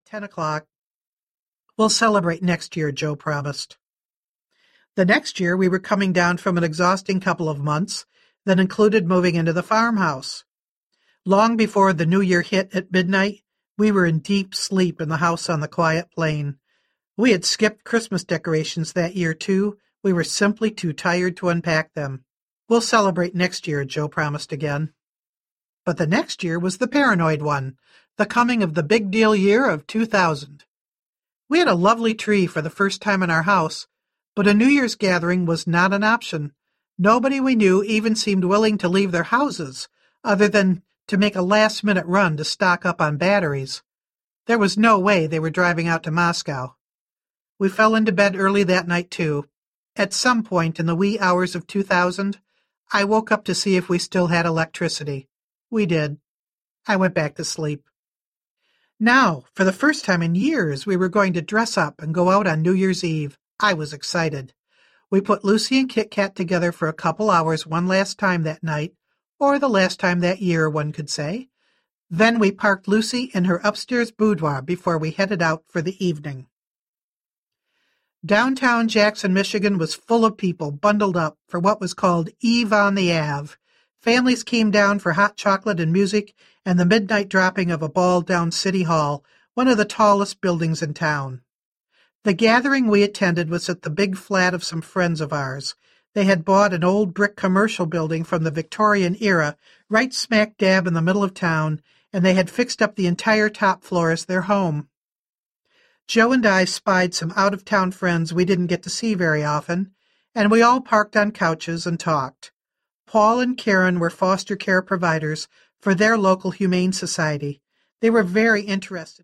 Kit Kat & Lucy Audiobook